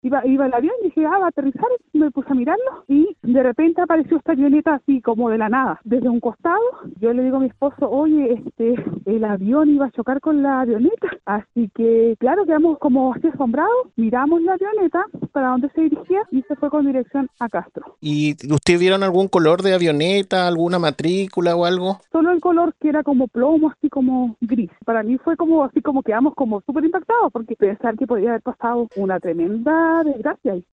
Testigo del incidente
Una auditora presenció el incidente y relató la situación: “Iba mirando el avión y dije ‘ah, va a aterrizar’ y de repente apareció esta avioneta, así como de la nada, desde un costado.
testigo-avion-1-.mp3